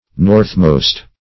northmost \north"most`\ (n[^o]rth"m[=o]st`), a. [AS.